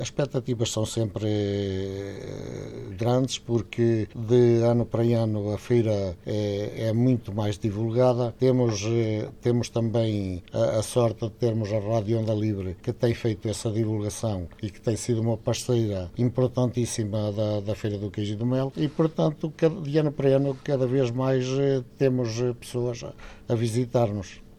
Durante estes três dias, as expectativas de receber muitos visitantes são sempre altas, acrescenta o presidente da União de Freguesias de Avidagos, Navalho e Pereira: